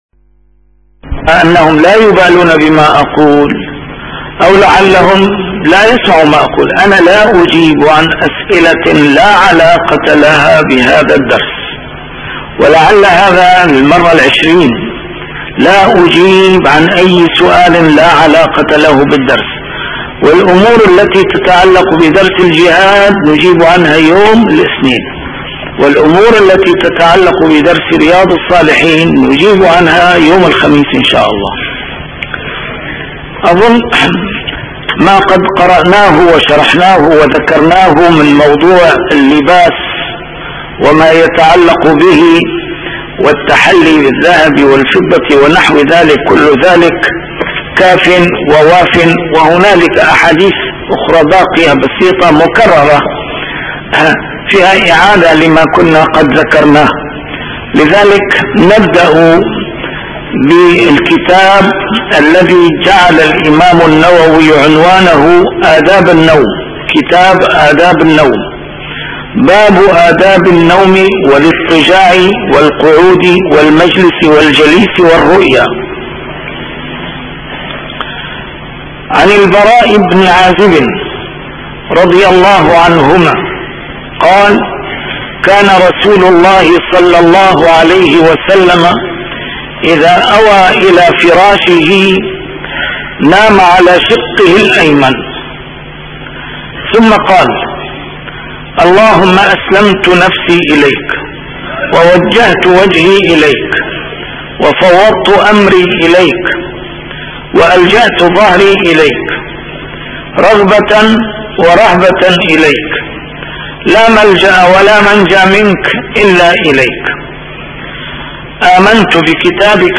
A MARTYR SCHOLAR: IMAM MUHAMMAD SAEED RAMADAN AL-BOUTI - الدروس العلمية - شرح كتاب رياض الصالحين - 669- شرح رياض الصالحين: آداب النوم والاضطجاع